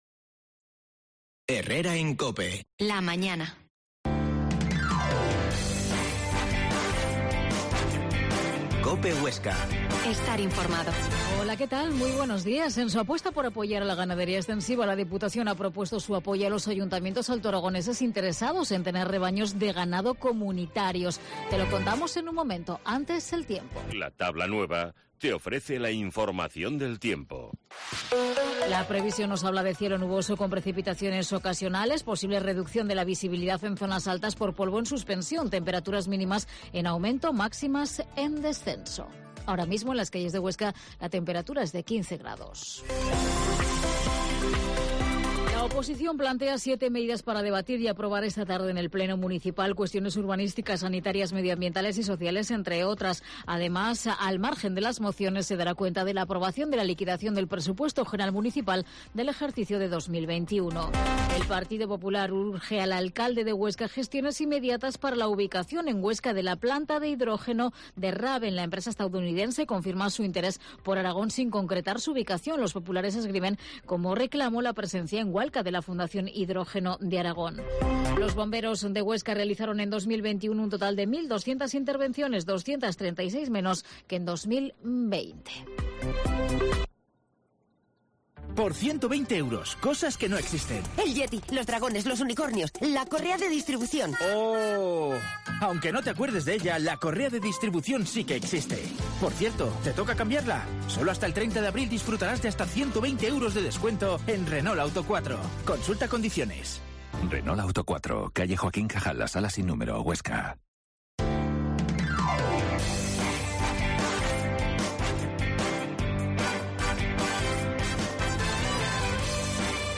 Herrera en COPE Huesca 12.50h Entrevista al Pte de la DPH, Miguel Gracia